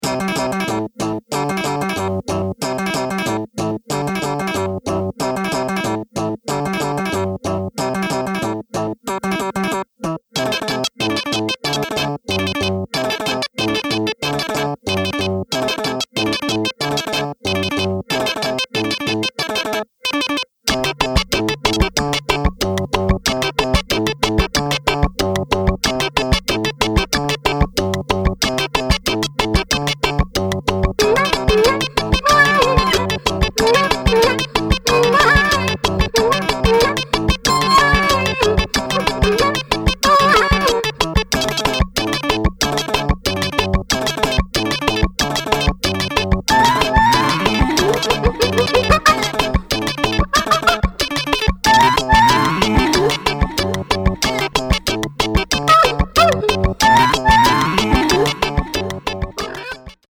明石とベルリンの遠隔セッションで制作されたという本作